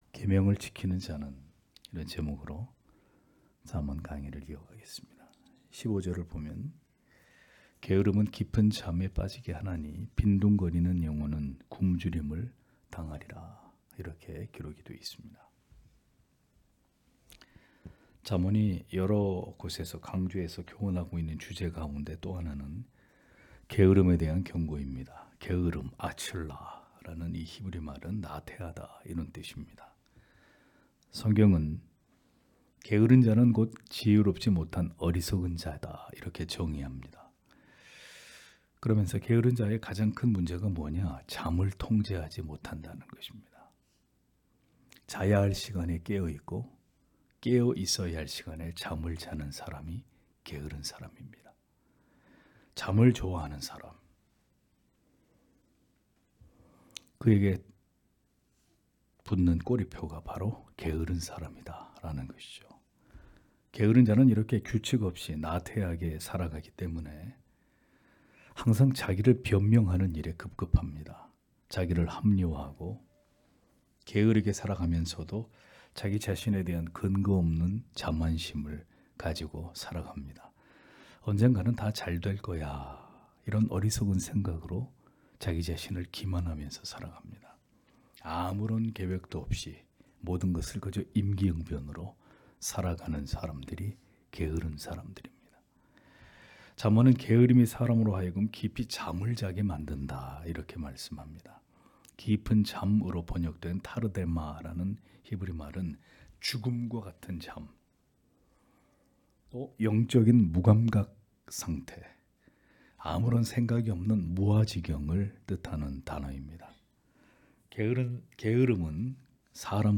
수요기도회 - [잠언 강해 116] 계명을 지키는 자는 (잠 19장 15-17절)